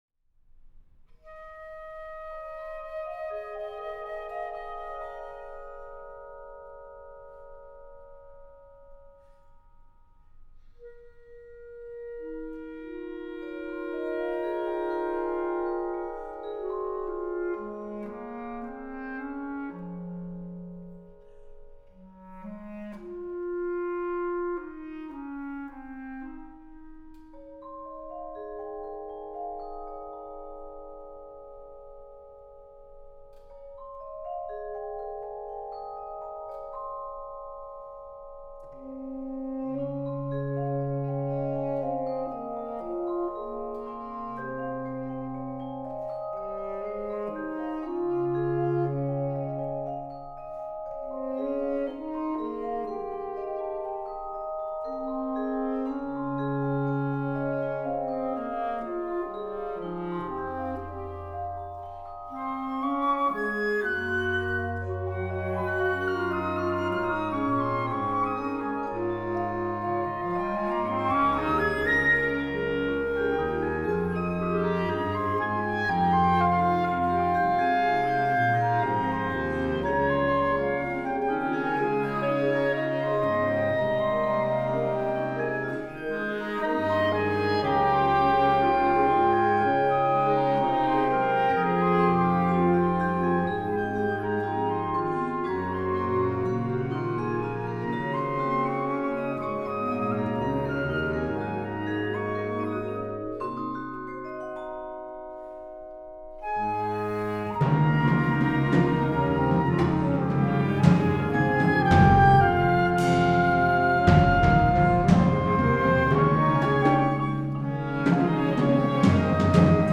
Partitions pour octuor flexible.